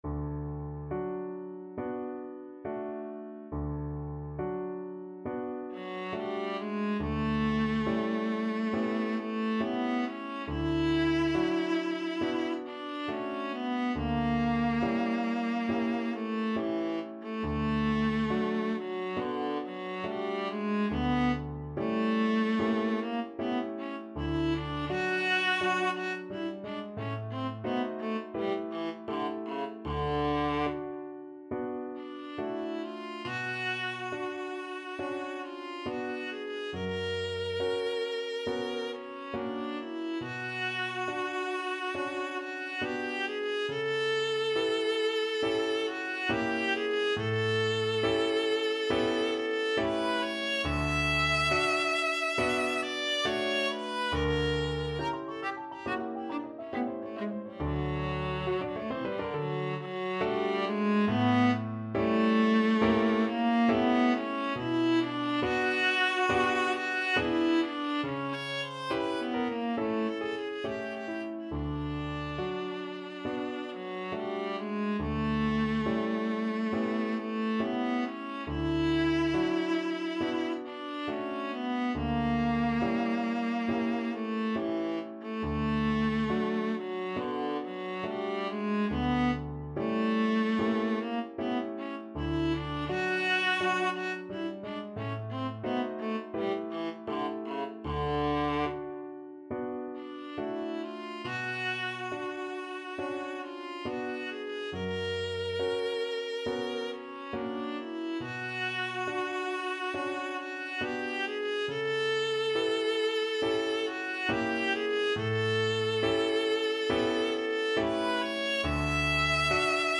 4/4 (View more 4/4 Music)
Andante non troppo con grazia =69
Db4-E6
Classical (View more Classical Viola Music)